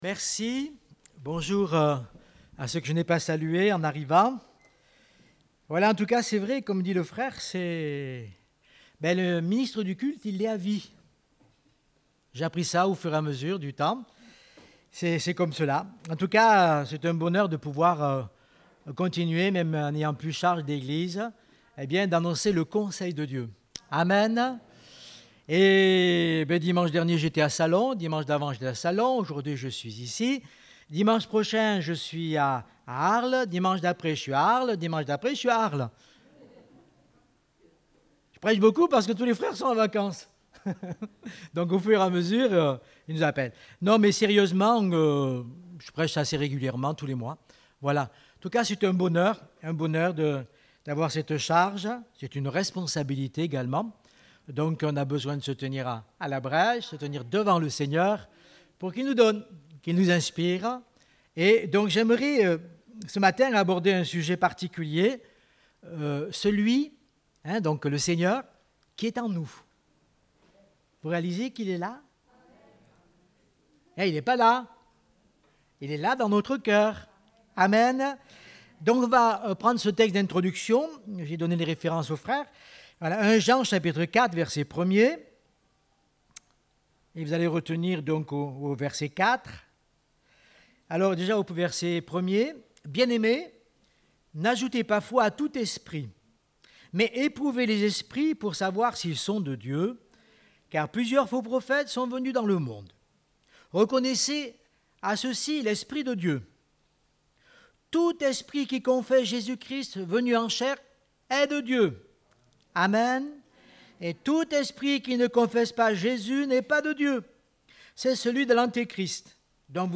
Date : 25 juillet 2021 (Culte Dominical)